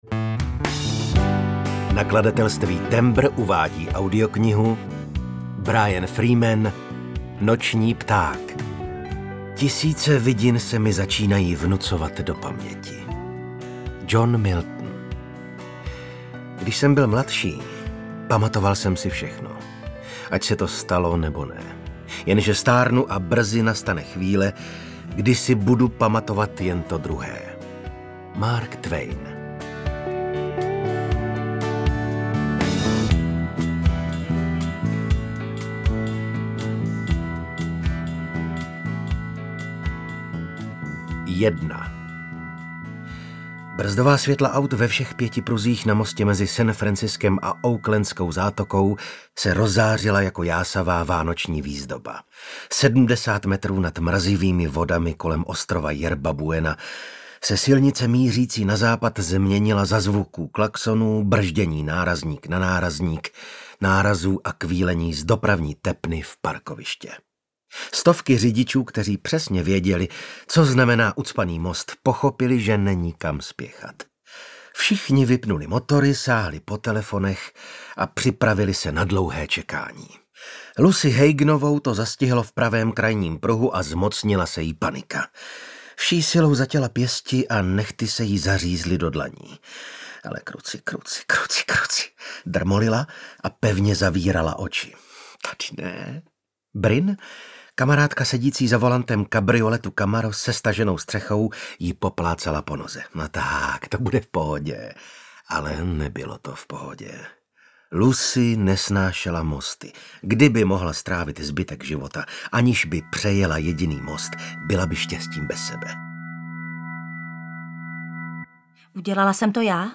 Noční pták audiokniha
Ukázka z knihy
• InterpretVasil Fridrich, Jana Stryková